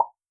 pop.wav